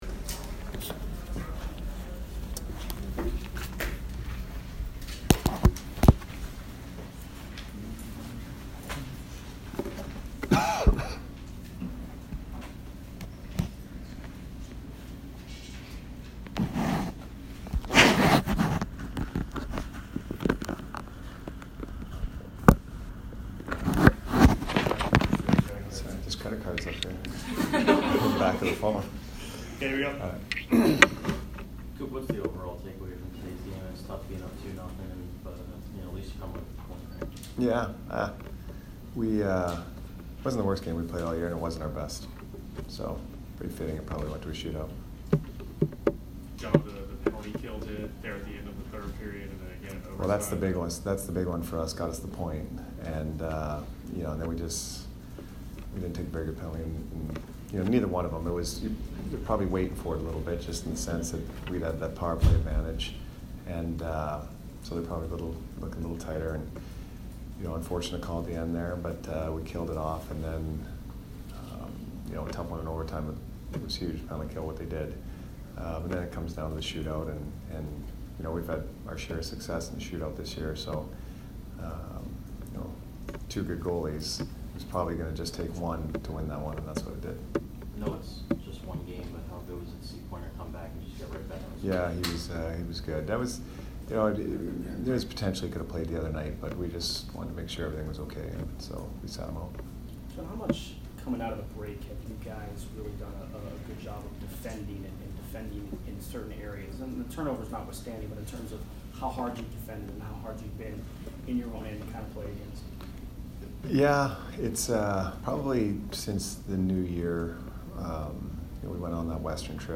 Jon Cooper post-game 2/5